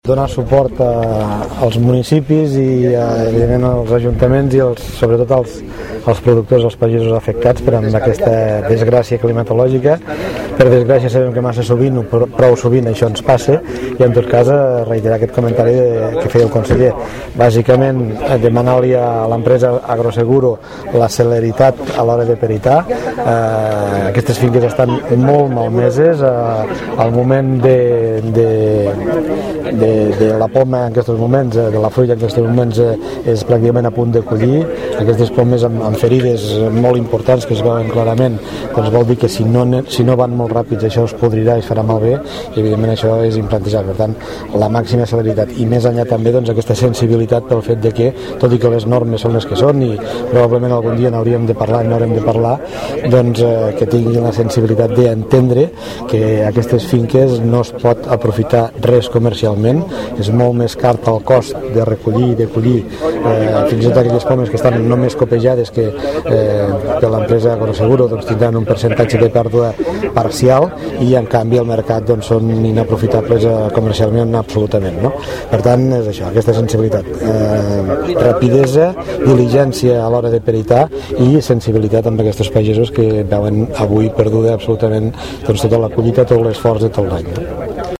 El president de la Diputació de Lleida, Joan Reñé, i el conseller d’Agricultura, Ramaderia, Pesca, Alimentació i Medi Natural, Josep Maria Pelegrí, han fet una visita d’urgècia a la zona de Castellnou de Seana afectada per la darrera pedregada que ha malmès diferentes plantacions de fruita de les comarques del Pla d’Urgell i de l’Urgell. En declaracions a la premsa, el president de la Diputació ha demanat celeritat i sensibilitat a l’empresa Agroseguro a l’hora de peritar els danys provocats per la pedra.
Castellnou de Seana, 1 de setembre de 2011